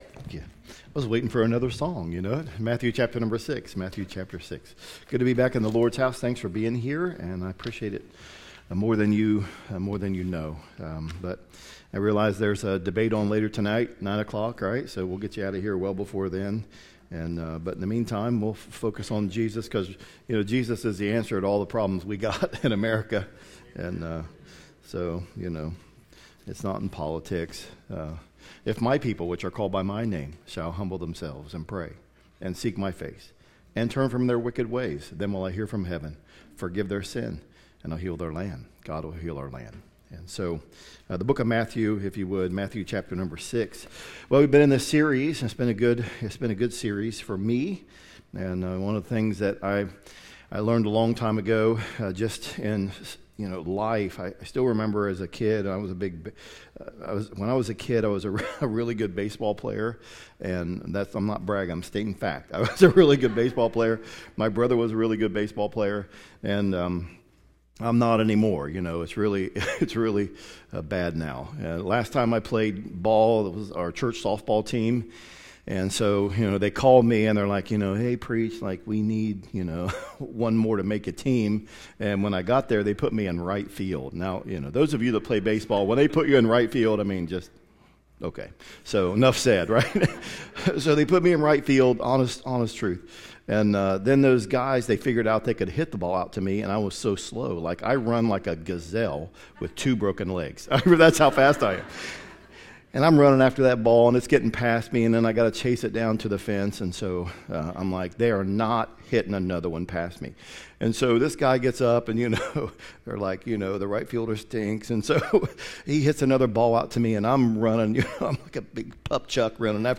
Fall Revival 2024 Lay Not Up/Clean Up – Lighthouse Baptist Church, Circleville Ohio
Fall Revival 2024 Lay Not Up/Clean Up